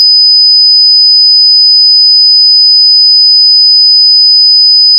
sinus_8000hz_5s.mp3